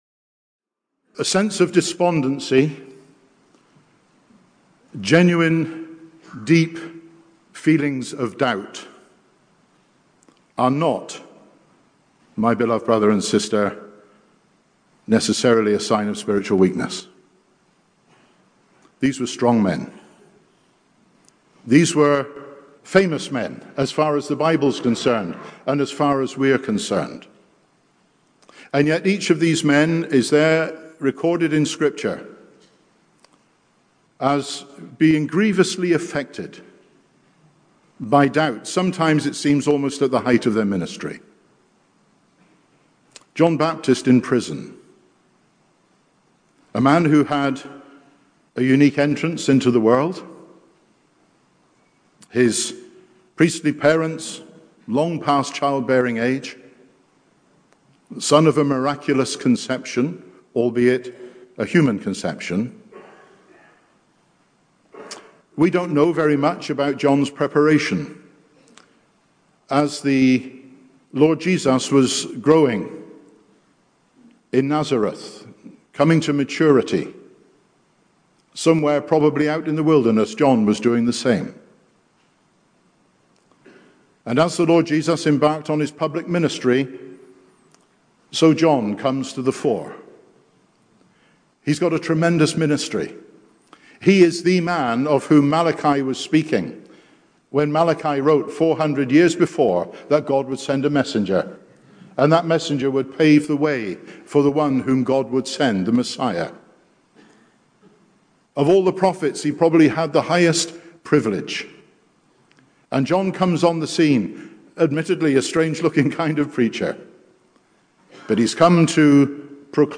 (Recorded in Belfast, Northern Ireland)